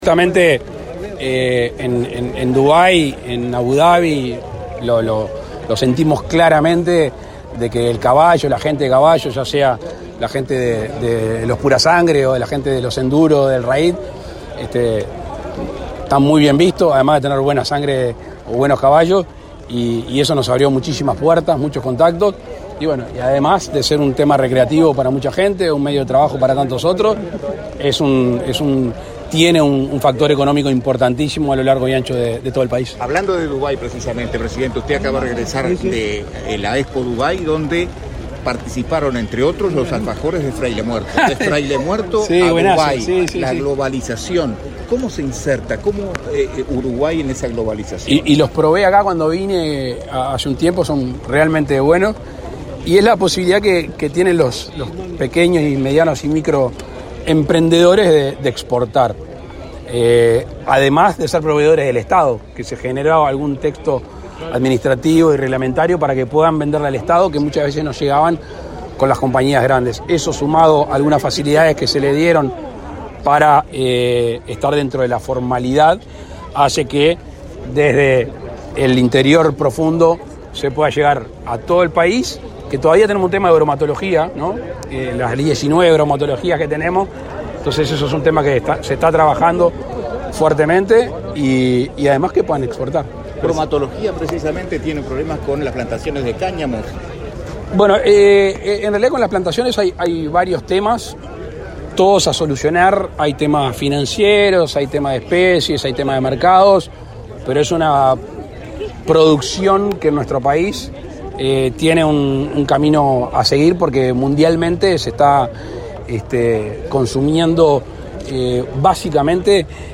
Declaraciones del presidente Lacalle Pou a la prensa